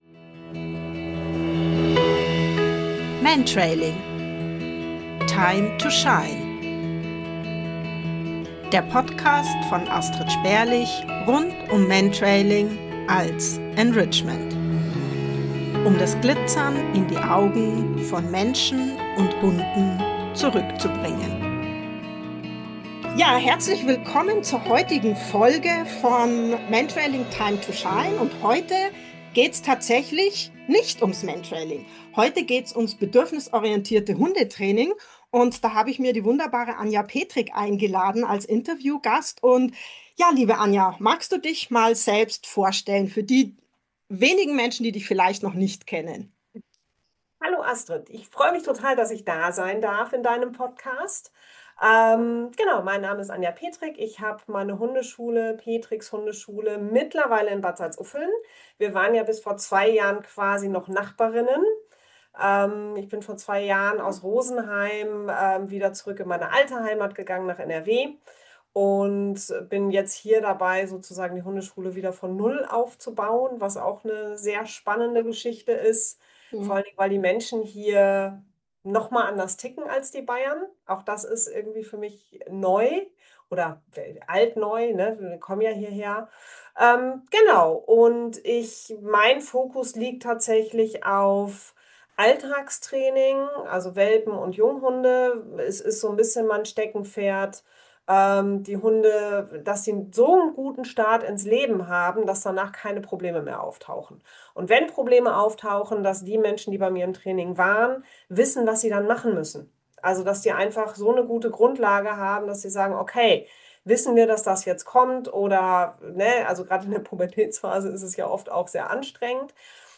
36 - Gelassenheit im Hundetraining - Ein Interview